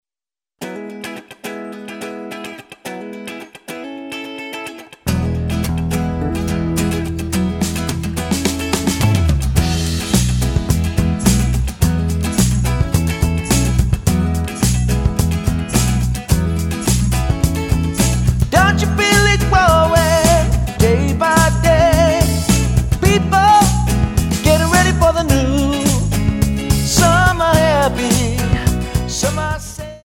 Tonart:E Multifile (kein Sofortdownload.
Die besten Playbacks Instrumentals und Karaoke Versionen .